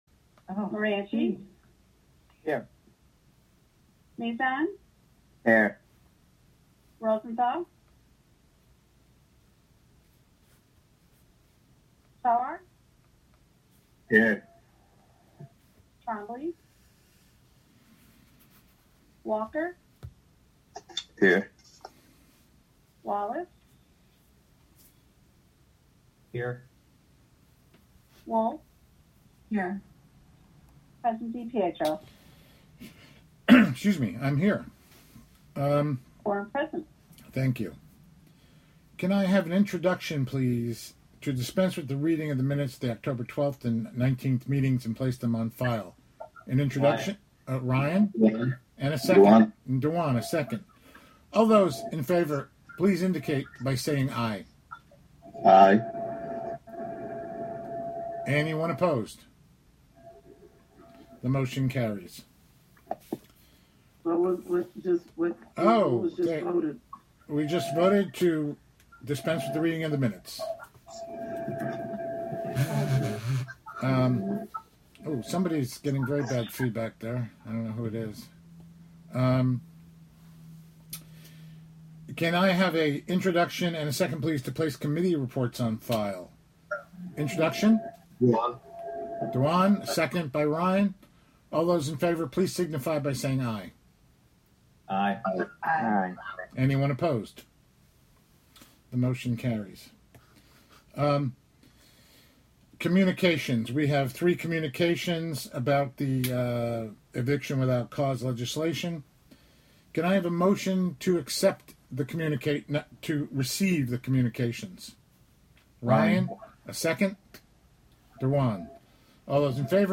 Live from the City of Hudson: Hudson Common Council Formal Meeting (Audio)